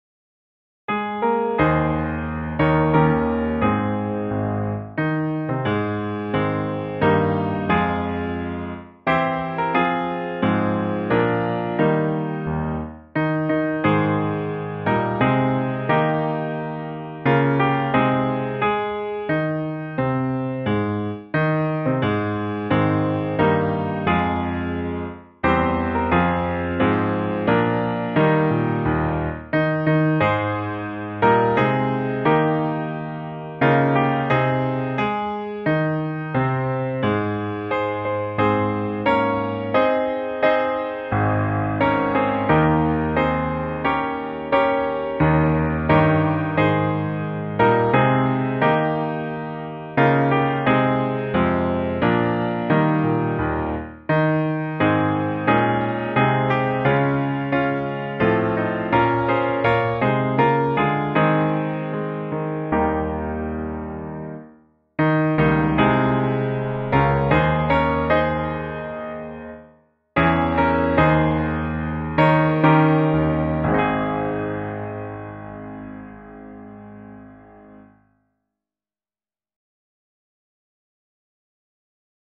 5th grade honor chorus (Graduation)